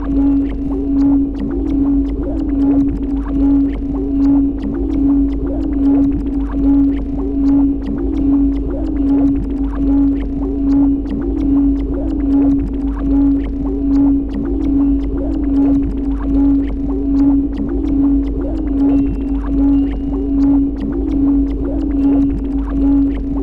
Recorded Bday 2010, Clips made August in Calais
Guitar_02_2_ResonenceClean.wav